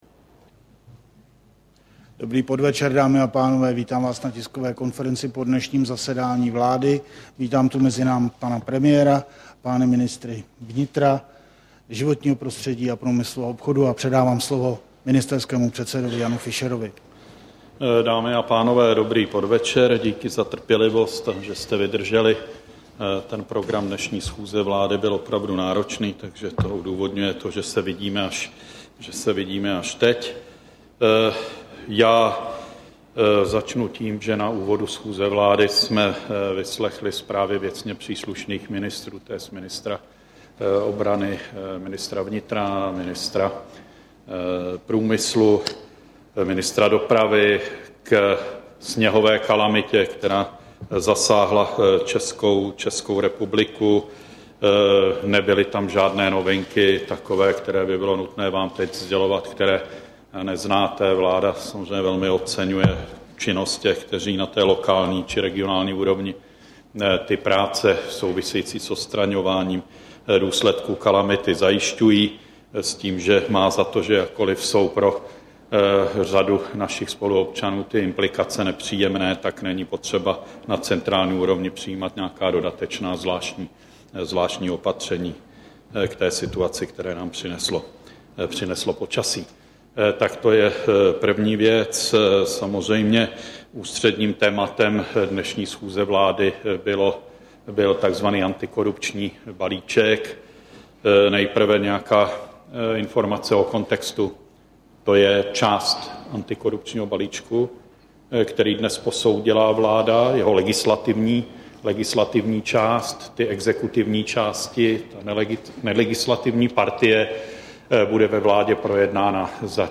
Tisková konference po zasedání vlády, 11. ledna 2010